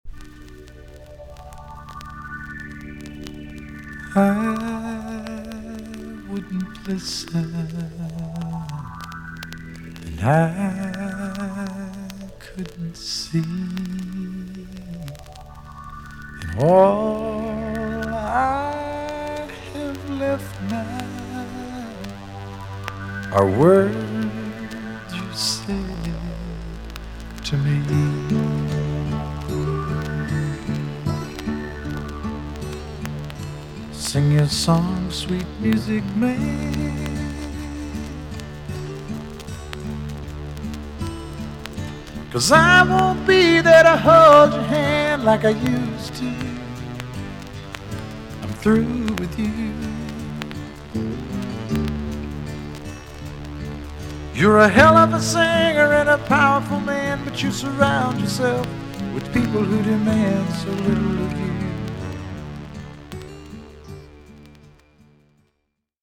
EX-~VG+ 少し軽いチリノイズがありますが良好です。